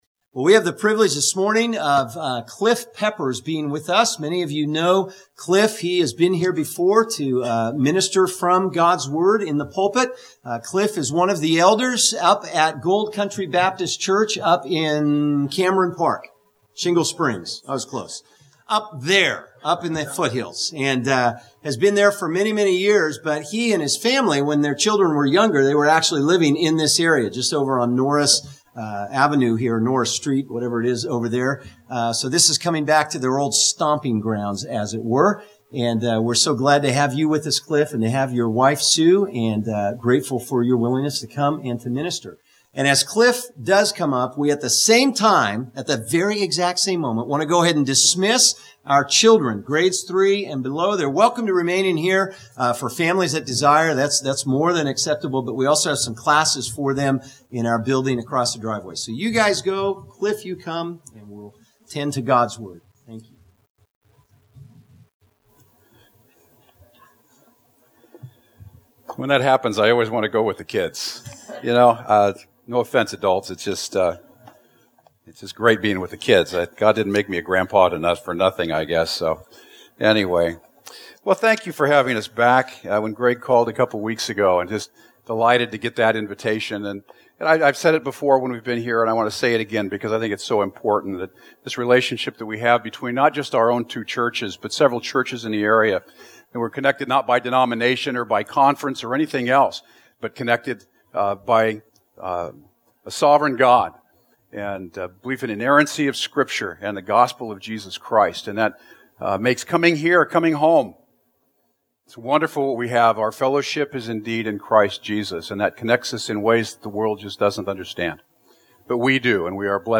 River City Grace Community Church Podcast: His Great and Precious Promises